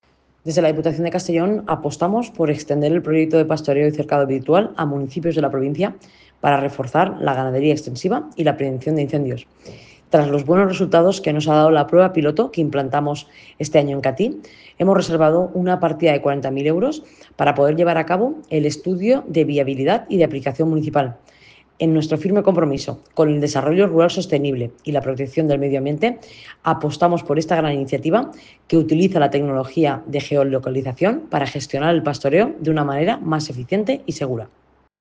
Presidenta-Marta-Barrachina-pastoreo-y-vallado-virtual.mp3